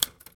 Index of /90_sSampleCDs/Roland L-CD701/PRC_FX Perc 1/PRC_Typewriter
PRC TYPKEY2.wav